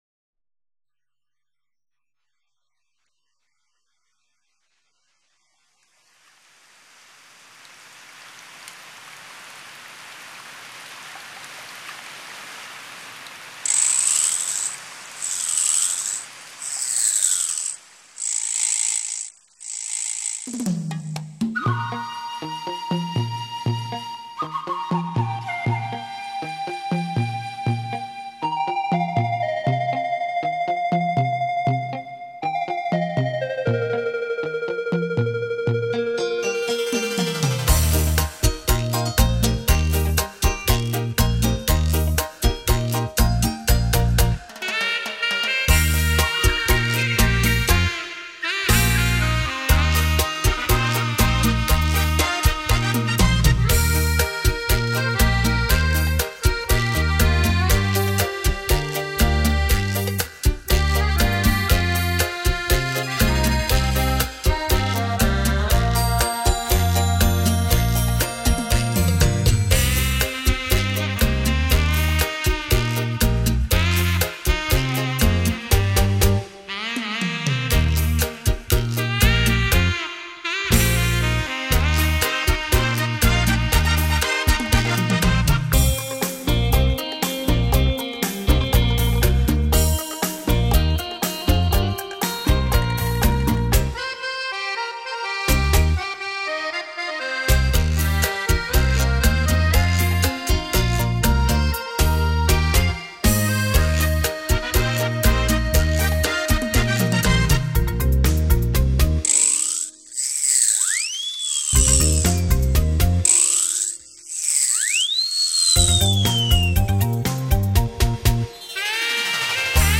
【电*脑*鼓*简*介】
杜比环绕音效录制